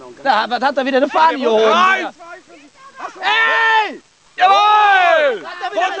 Bei den aufgenommenen Torsequenzen fallen im Hintergrund immer wieder irgendwelche komischen Kommentare, man hört Jubelschreie oder wilde Diskussionen...